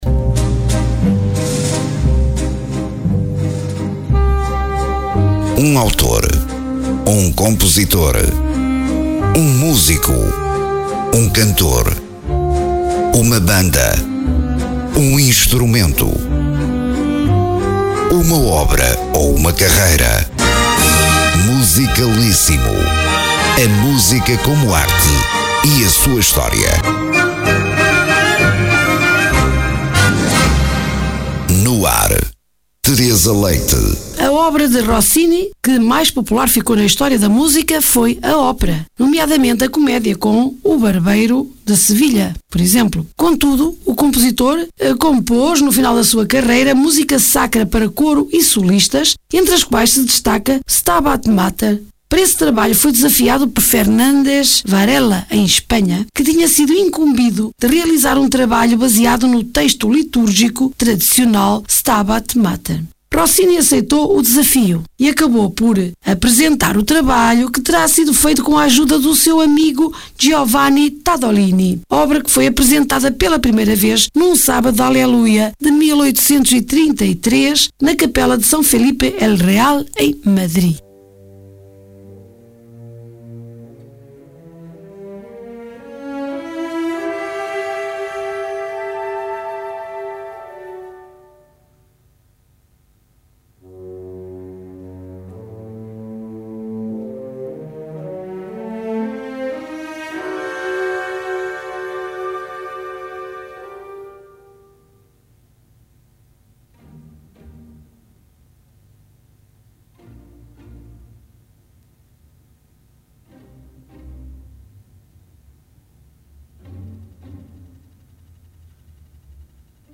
música sacra para coro e solistas
escrito em 1841 para solo de tenor